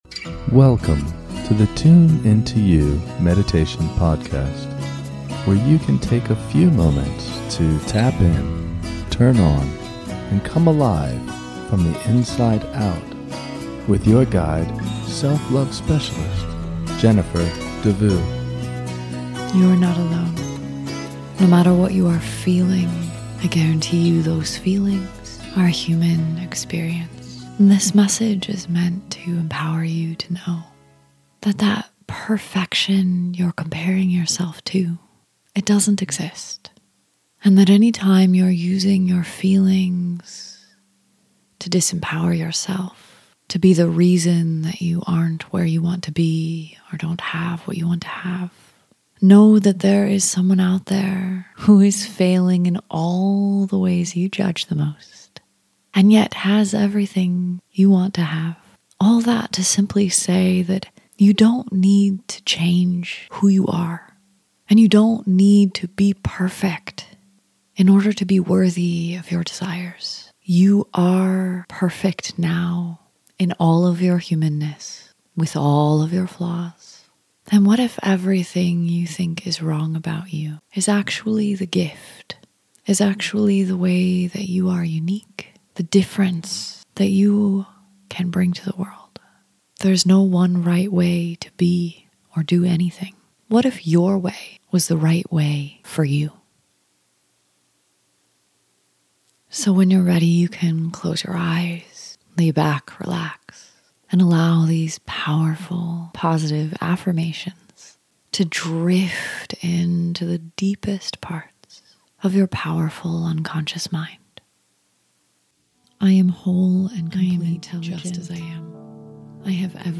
This track includes a series of overlapping positive affirmations to support you in building self-esteem and self-love.
This meditative audio is designed to get you back on track with believing in and living from your highest wisdom. Sit back, relax, and allow the soothing messages of this recording to enter your subconscious mind.